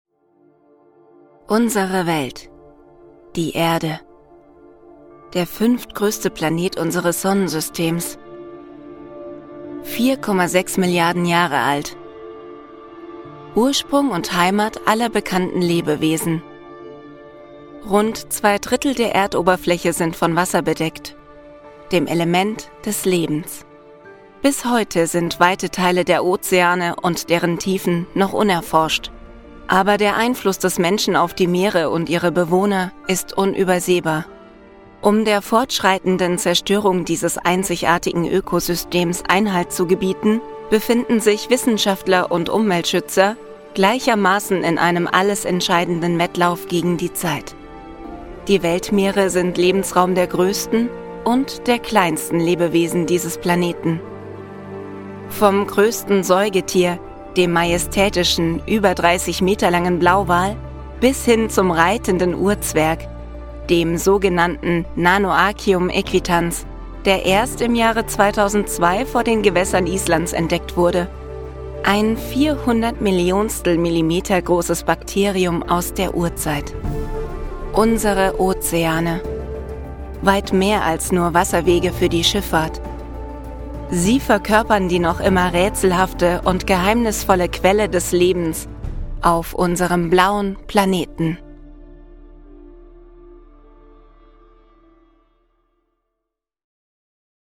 Demo | Natur-Doku | Kommentar